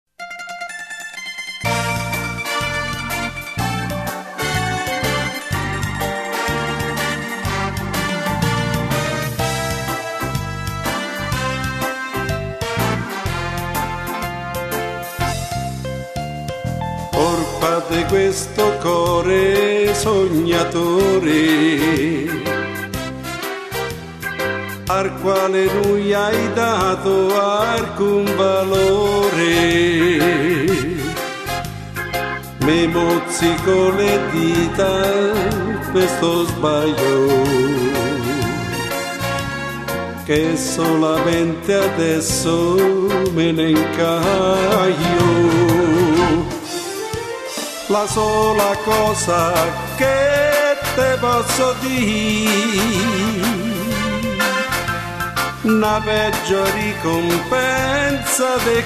Beguine
8 Brani di musica popolare romana